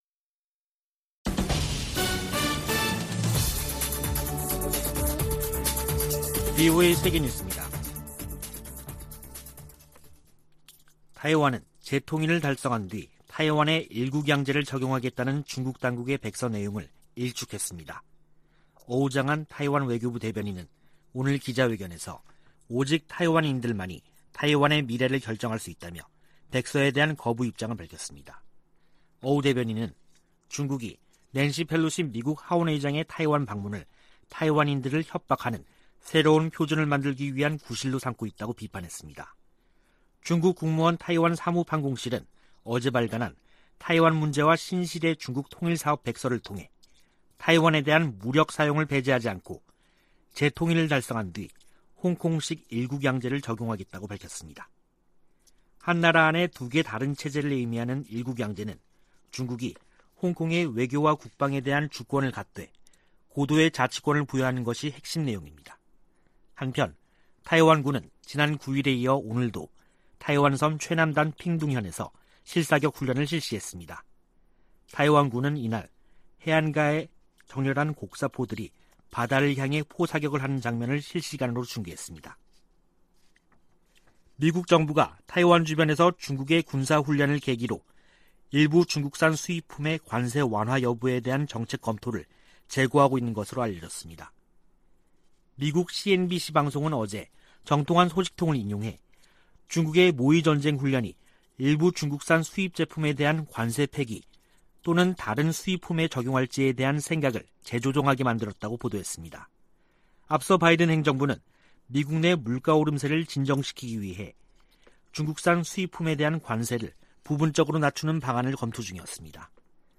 VOA 한국어 간판 뉴스 프로그램 '뉴스 투데이', 2022년 8월 11일 3부 방송입니다. 미 국무부는 중국의 사드와 관련한 한국에 대한 이른바 3불1한 주장은 부적절하다고 지적했습니다. 한국 대통령실 측은 사드는 북한 핵과 미사일로부터 국민을 지키기 위한 자위 방어 수단이라며 협의 대상이 결코 아니라고 강조했습니다. 북한의 거듭된 탄도미사일 발사가 한반도의 긴장을 고조시키고 있다고 아세안지역안보포럼 외교장관들이 지적했습니다.